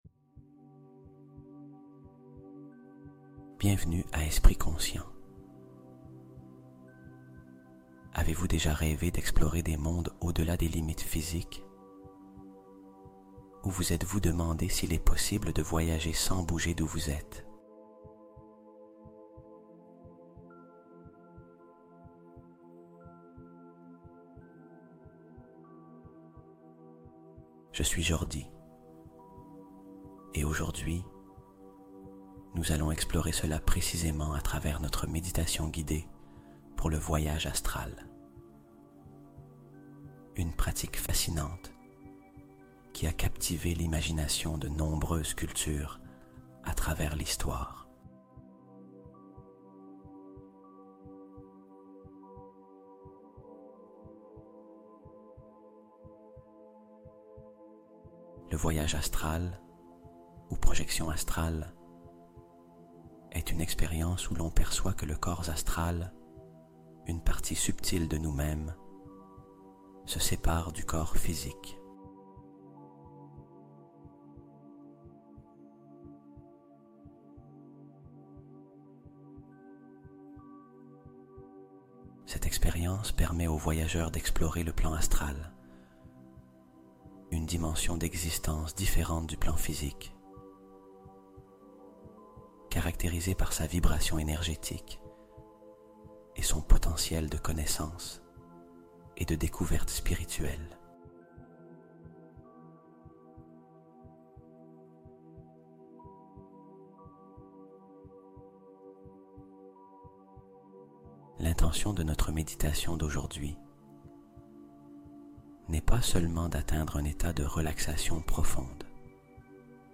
Voyage de la Conscience : Initiation guidée à l'exploration des plans subtils